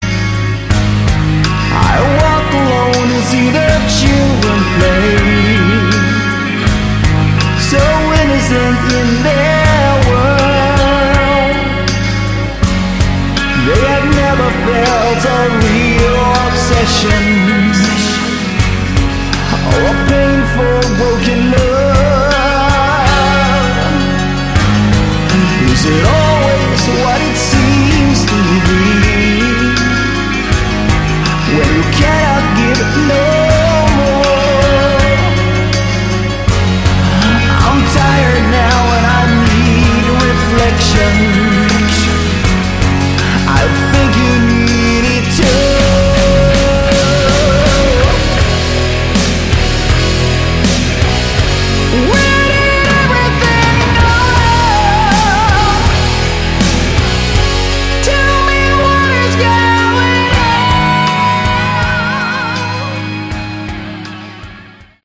Category: Hard Rock
vocals, backing vocals
guitars, keyboards, backing vocals